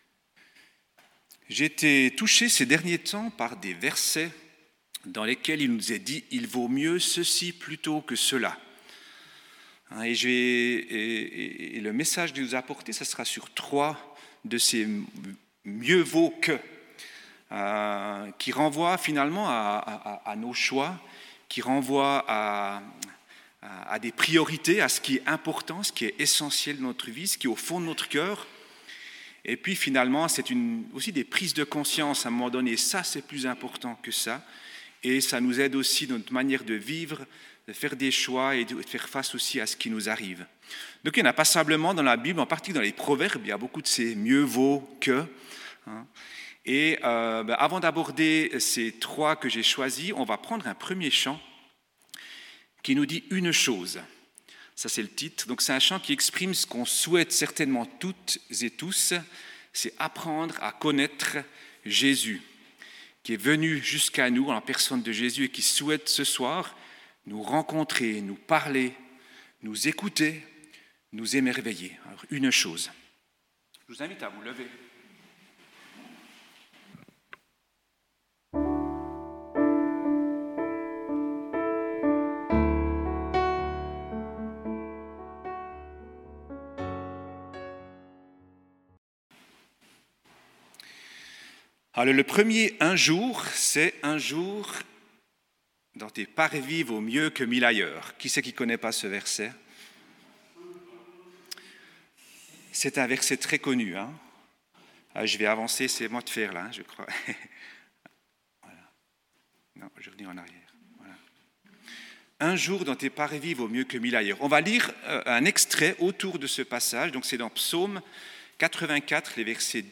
Type De Rencontre: Culte